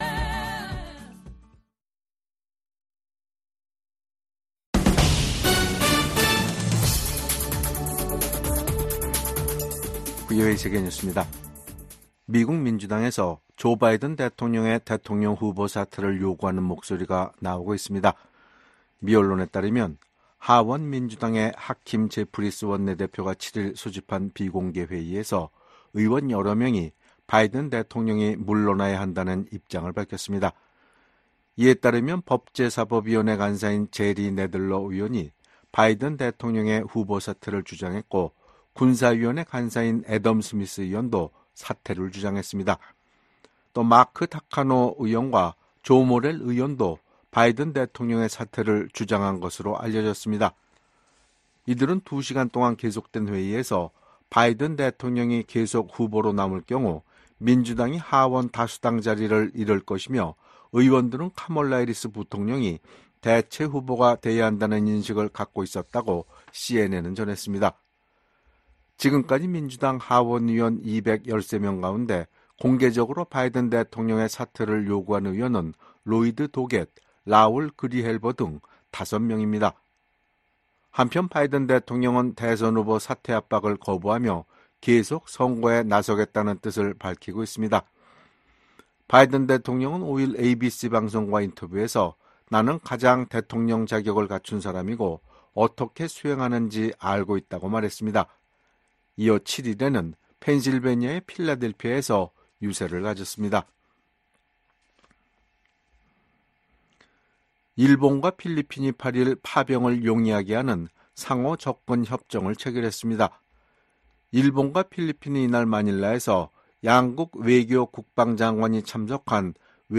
VOA 한국어 간판 뉴스 프로그램 '뉴스 투데이', 2024년 7월 8일 3부 방송입니다. 북한의 핵과 미사일 프로그램이 주변국과 세계안보에 대한 도전이라고 나토 사무총장이 지적했습니다. 윤석열 한국 대통령은 북-러 군사협력이 한반도와 국제사회에 중대한 위협이라며 한-러 관계는 전적으로 러시아에 달려 있다고 말했습니다. 미국 고위관리는 중국의 러시아 지원이 전쟁을 부추기고 있으며, 워싱턴 나토 정상회의에서 이 문제가 중요하게 논의될 것이라고 밝혔습니다.